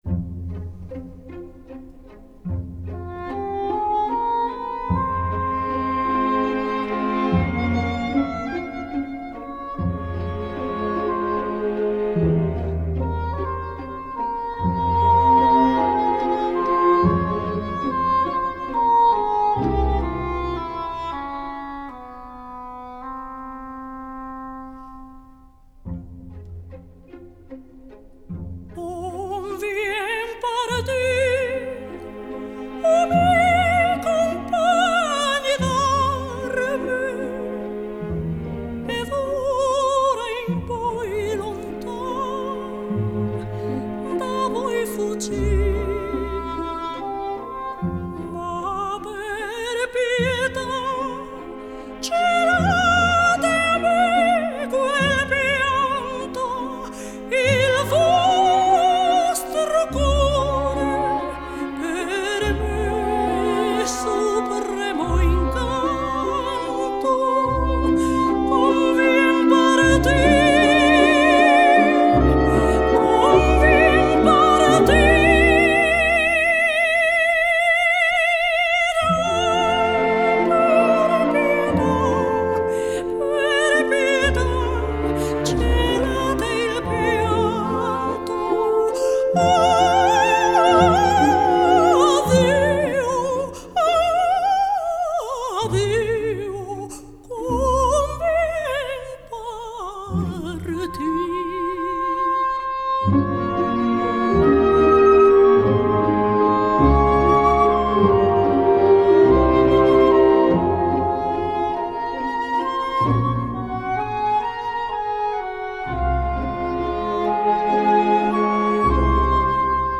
исполнителя популярных оперных арий